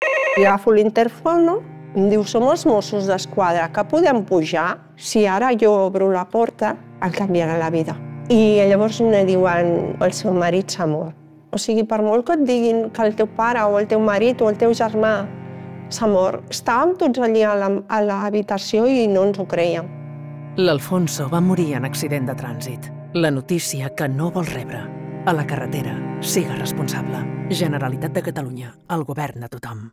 La noticia que no vols rebre_falca2.wav